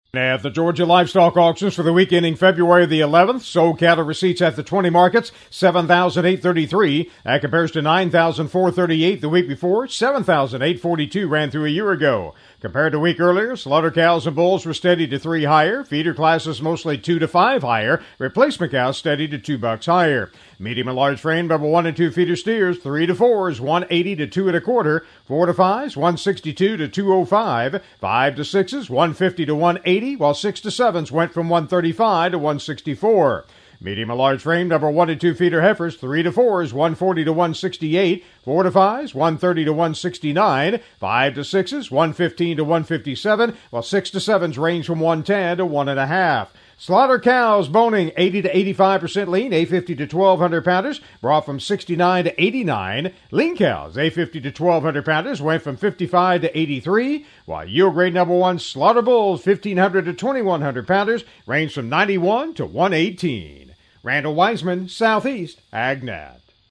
GA Weekly Livestock Market Report